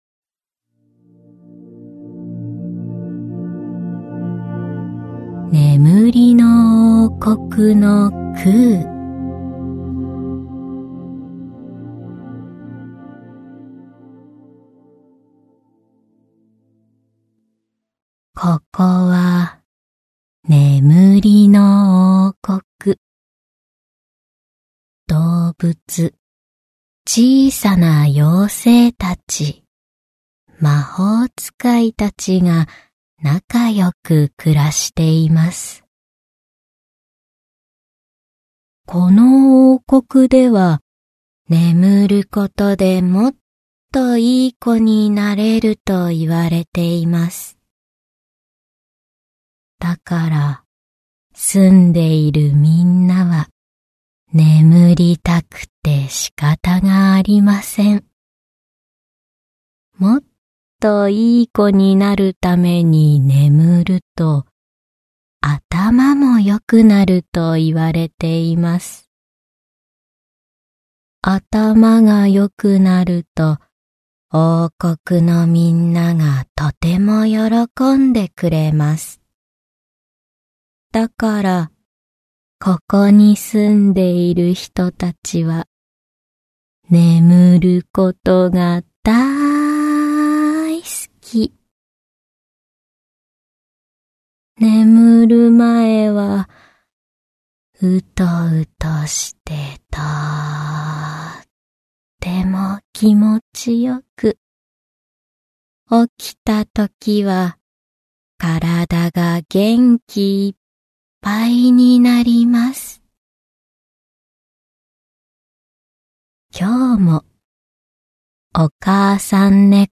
[オーディオブック] おやすみ絵本 ねむりの王国のクウ